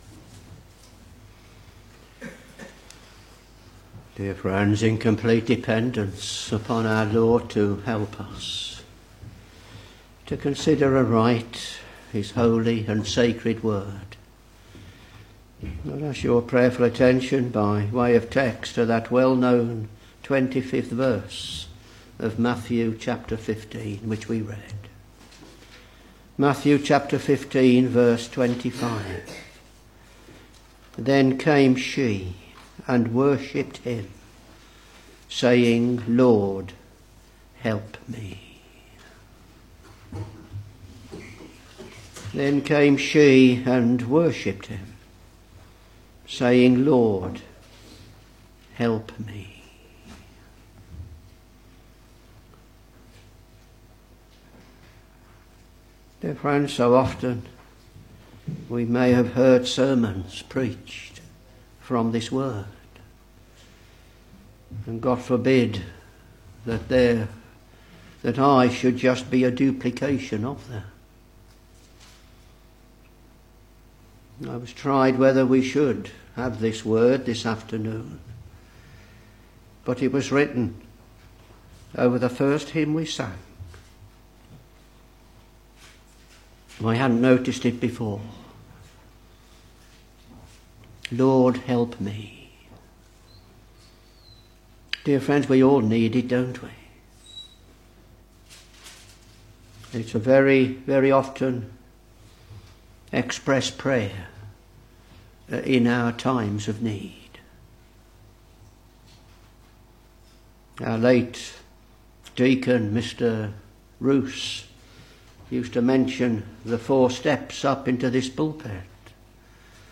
Back to Sermons Matthew Ch.15 v.25 Then came she and worshipped him, saying, Lord, help me.